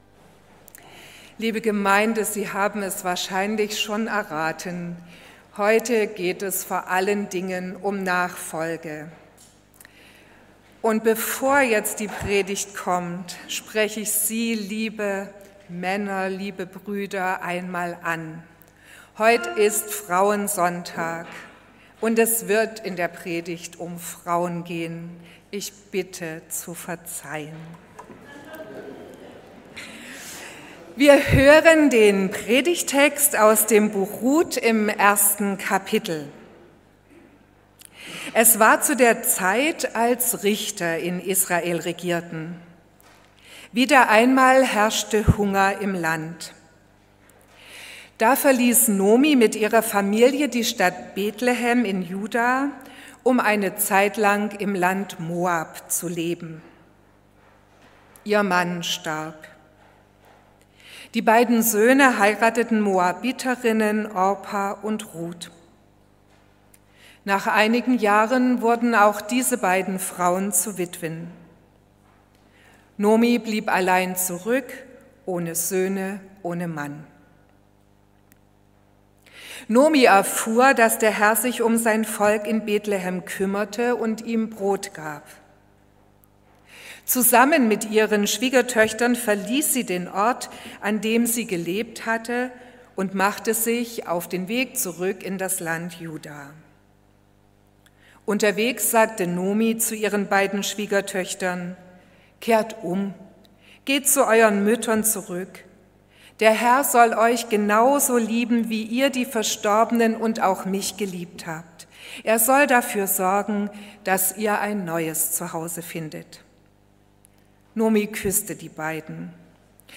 Die Predigten aus den Gottesdiensten der letzten drei Monate können als MP3-Datei heruntergeladen und nachgehört werden.